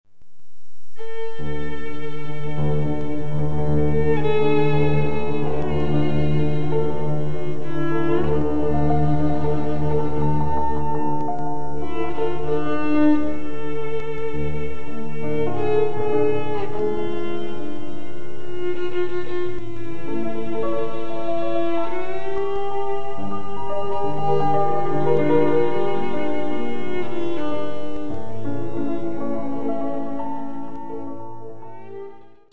- folklore russe: